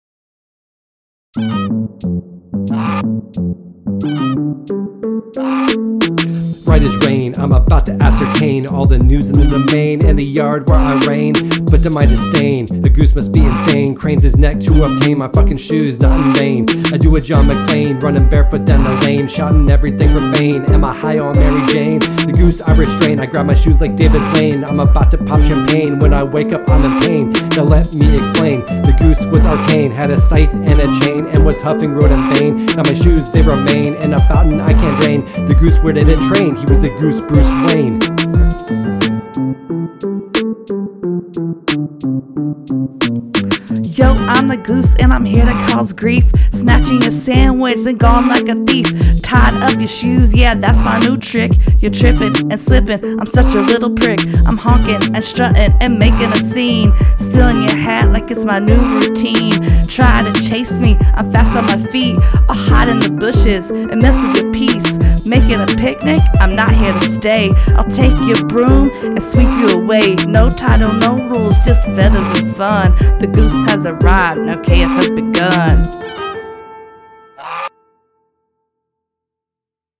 Rap from Episode 86: Untitled Goose Game – Press any Button